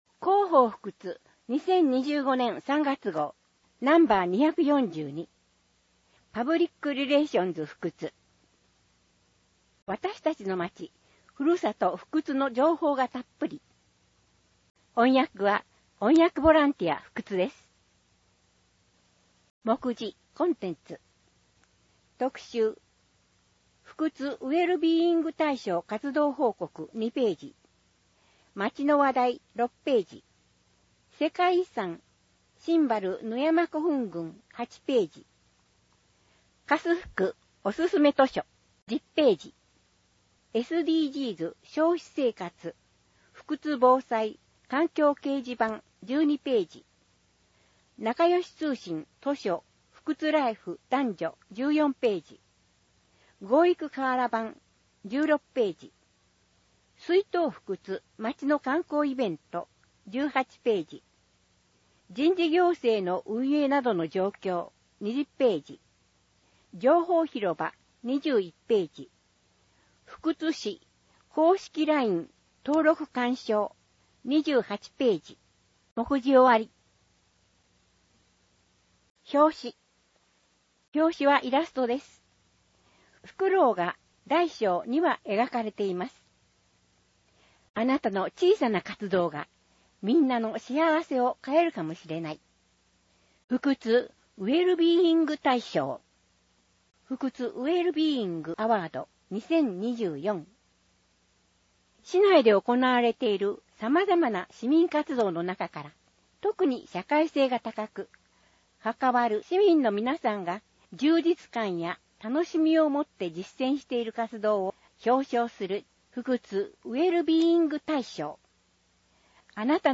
広報ふくつを音声で聞けます
音訳ボランティアふくつの皆さんが、毎号、広報ふくつを音訳してくれています。